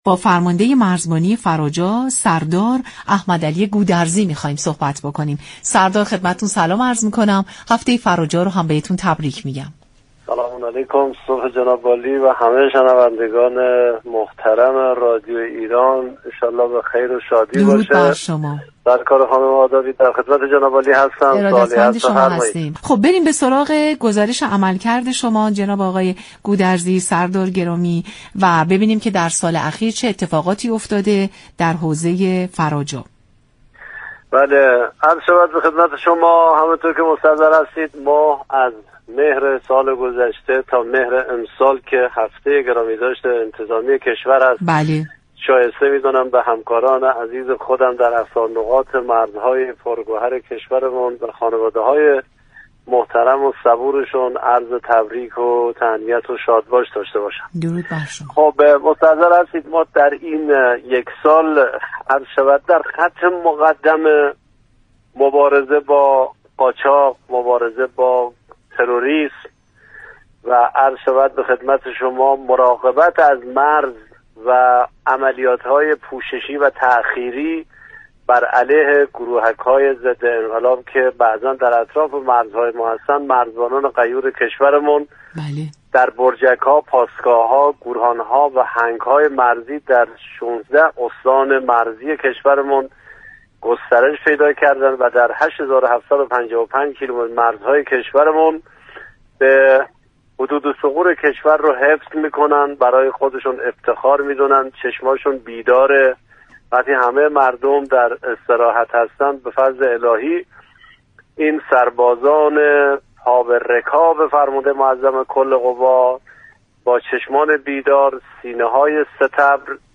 به گزارش فضای مجازی رادیو ایران، احمدعلی گودرزی فرماندهی مرزبانی فراجا در برنامه سلام‌صبح‌بخیر درباره به بررسی عملكرد یك‌سال اخیر پرداخت و گفت: مرزبانی فراجا در یكسال اخیر در خط مقدم مبارزه با قاچاق قدم، تروریست و مراقبت از مرز، عملیات‌های پوششی و تاخیری علیه كشور بوده‌ است.